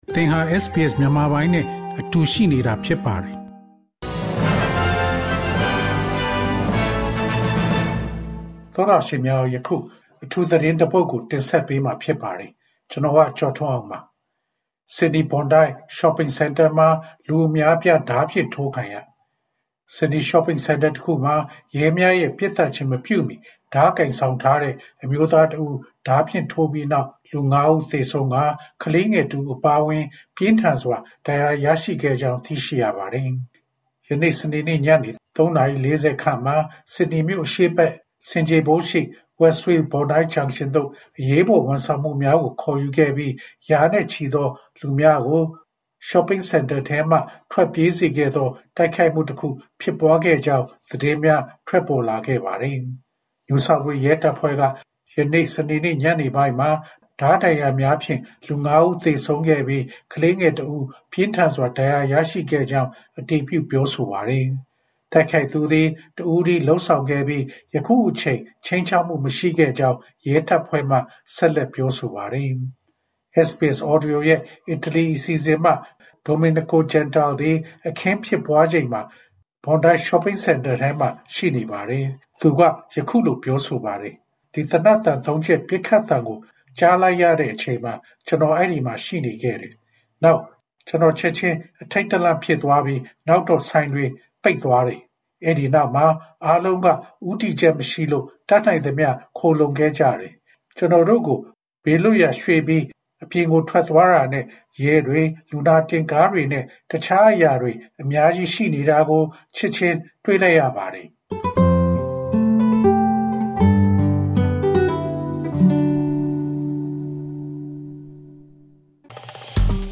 SBS Breaking News